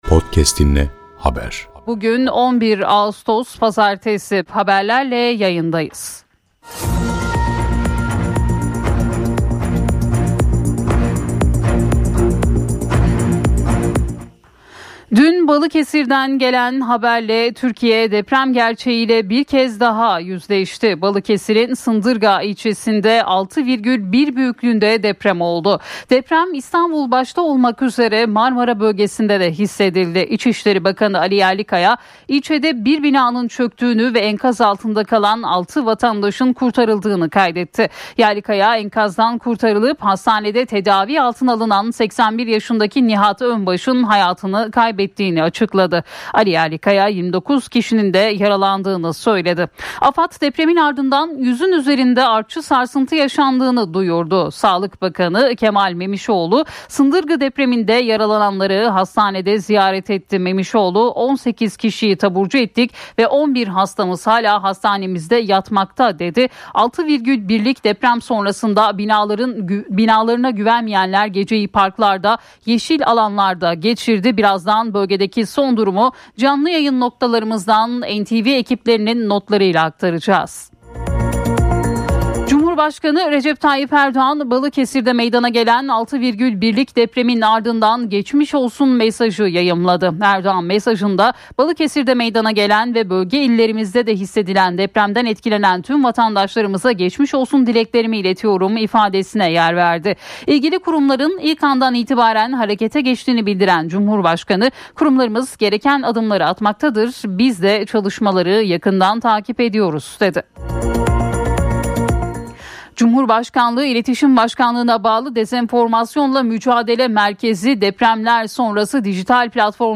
Dinle Gündem 11 Ağustos 2025 📌 Balıkesir 6,1’lik depremle sallandı: 1 kişi öldü, 6 kişi enkazdan çıkartıldı 📌 Bölgeden canlı bağlantılar
Uzman görüşleri